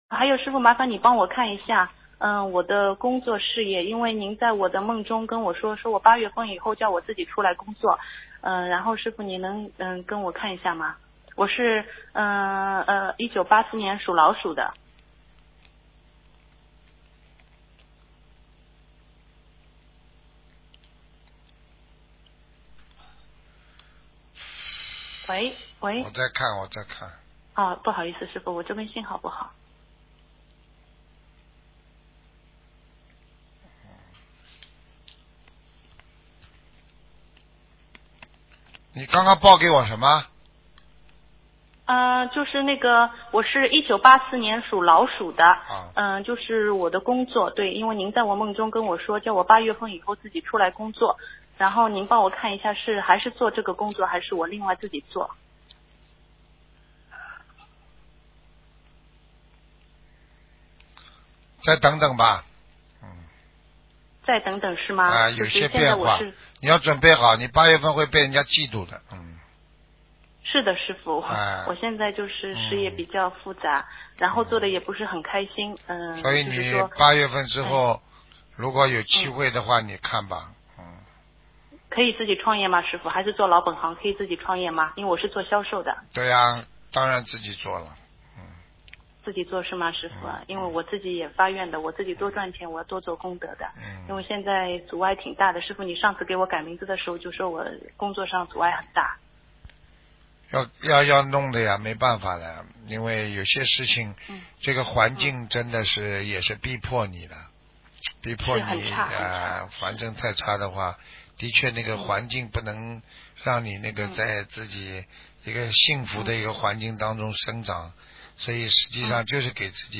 目录：剪辑电台节目录音_集锦